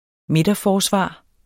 Udtale [ ˈmedʌˌfɒːˌsvɑˀ ]